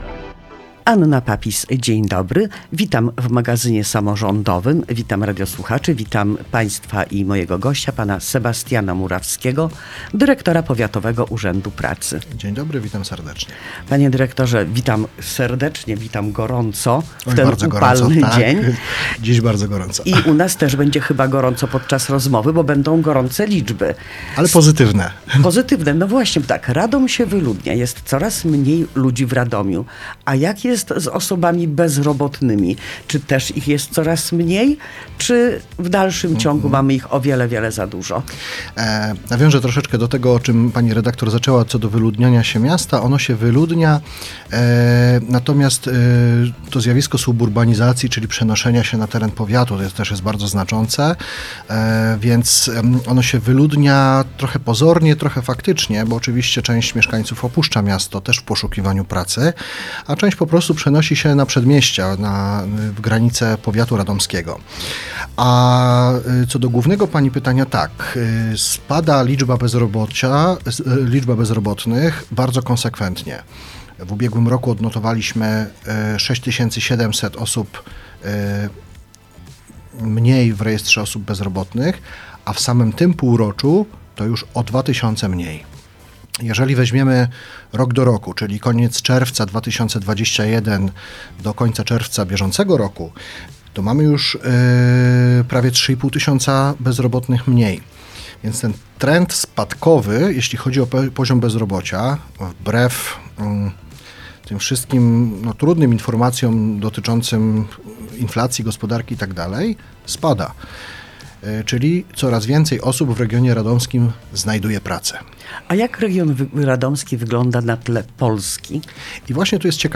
w studiu Radia Radom